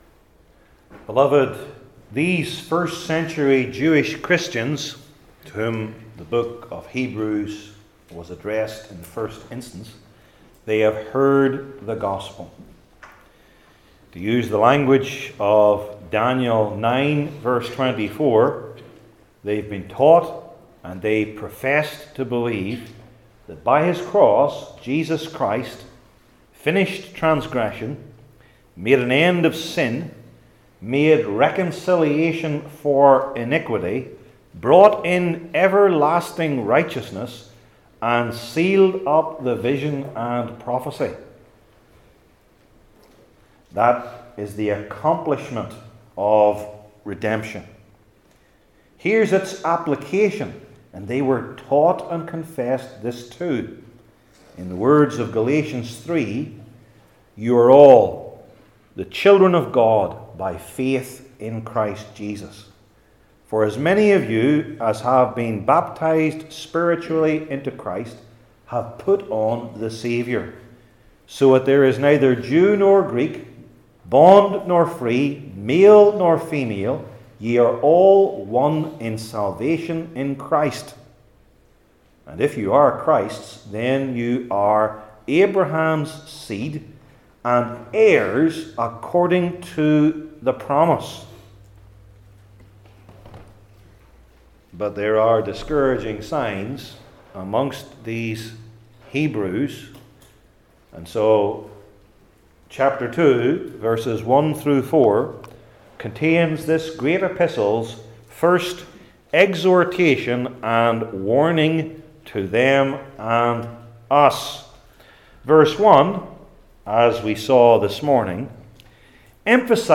Hebrews 2:2-4 Service Type: New Testament Sermon Series I. The Speakers II.